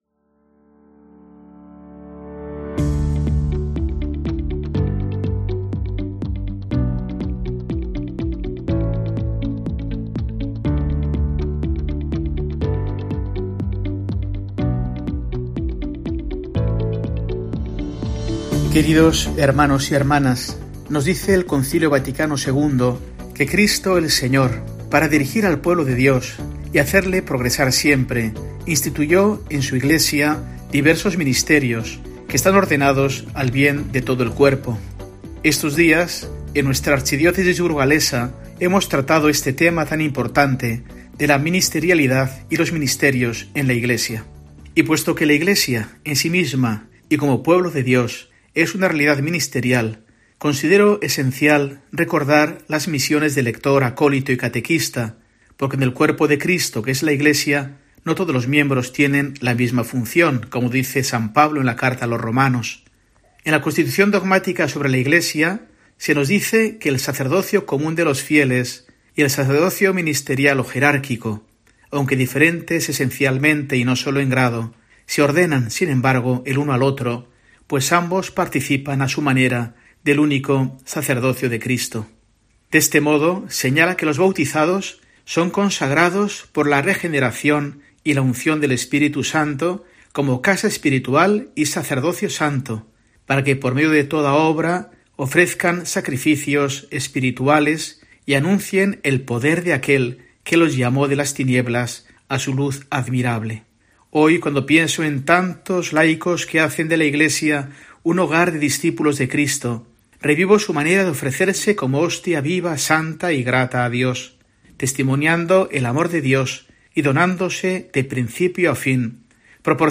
Mensaje del arzobispo de Burgos para el domingo, 10 de marzo de 2024